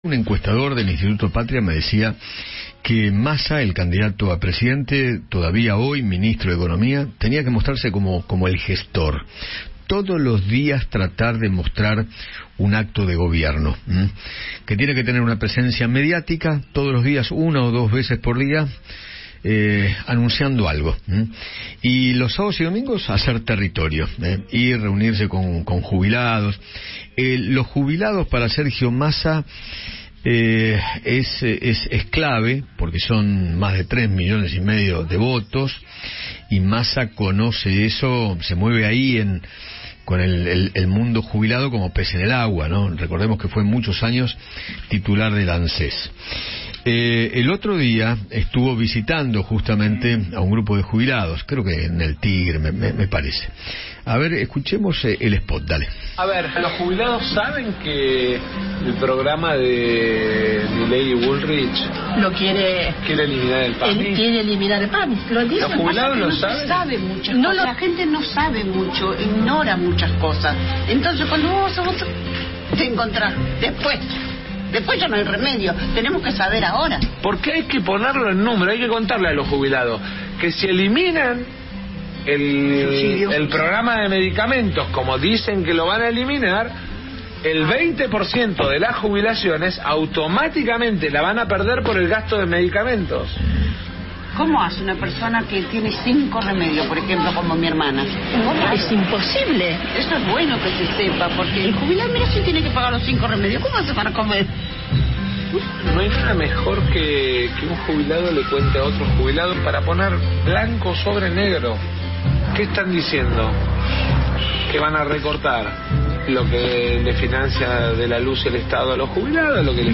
Eugenio Semino, Defensor del Pueblo de la Tercera Edad, conversó con Eduardo Feinmann sobre la pérdida económica que sufrieron los jubilados en el último año.